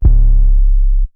pbs - mexiko dro [ 808 ].wav